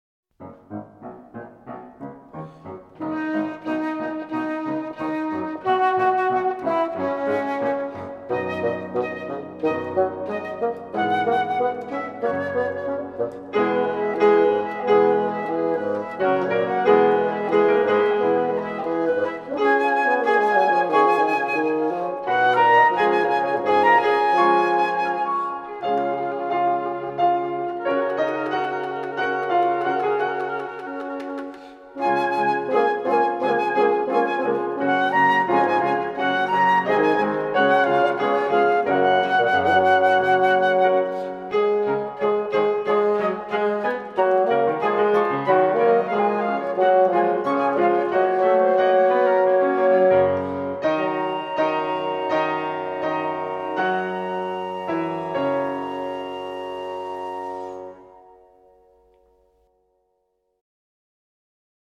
Suite for Flute, Bassoon and Piano: 3rd movement